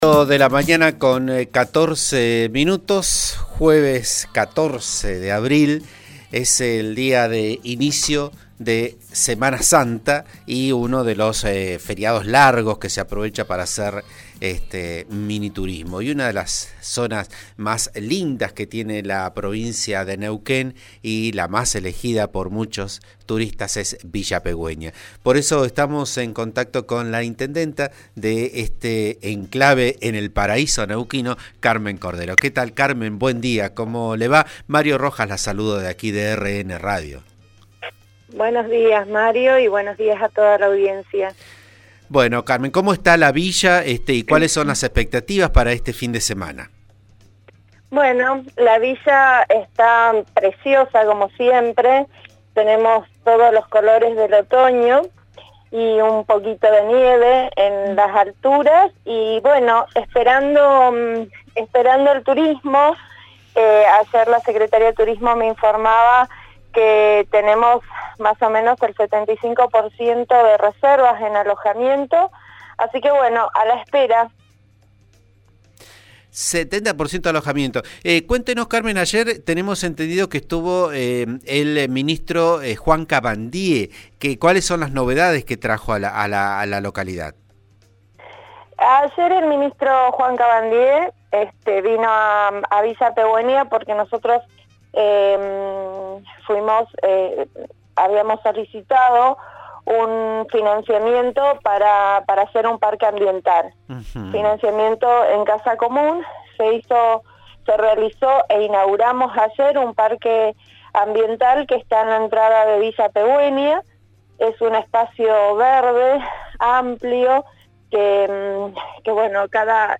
Escuchá a Carmen Cordero, intendenta de Villa Pehuenia Moquehue, en «Vos a Diario» por RN RADIO: